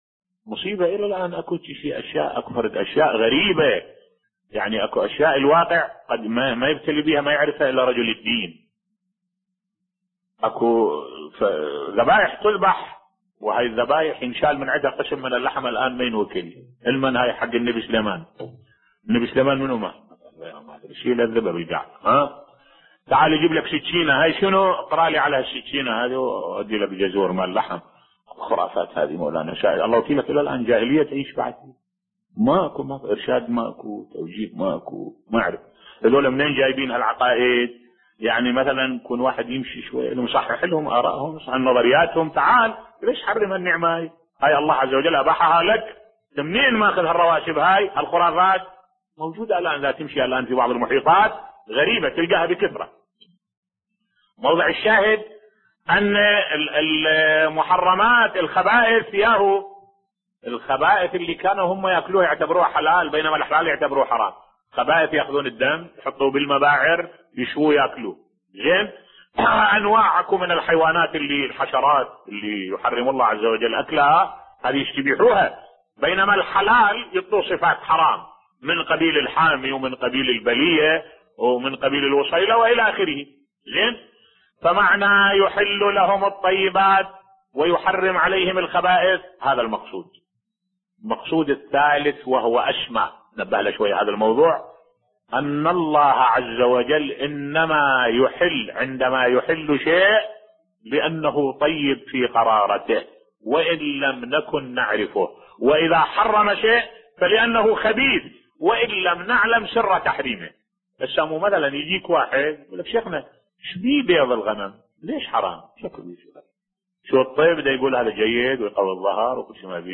ملف صوتی لماذا بيض الغنم حرام بصوت الشيخ الدكتور أحمد الوائلي